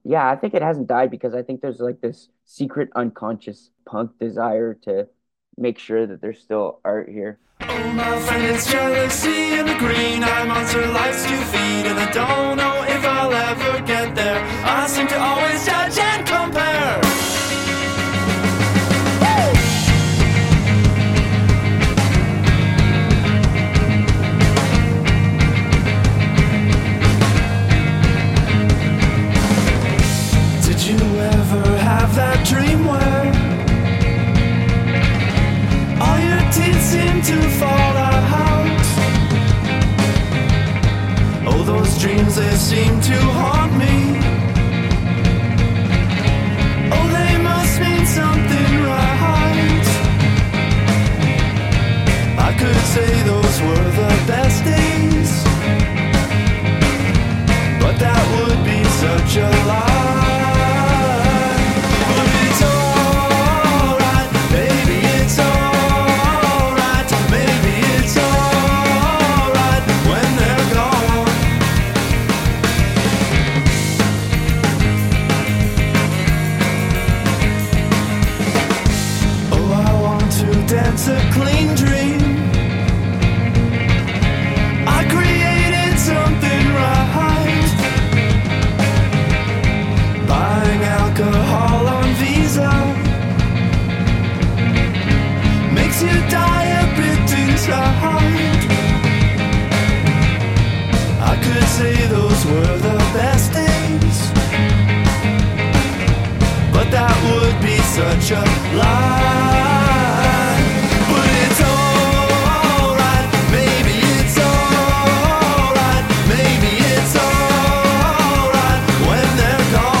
TIME NOT WASTED: a radio documentary looking back at 30 years of Music Waste Festival.
All music featured in this episode can be found in citr’s music library.